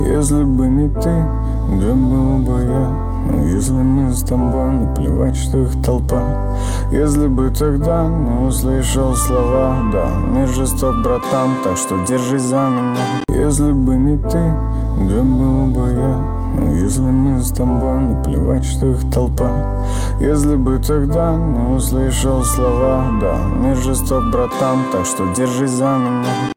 Жанр: Русские песни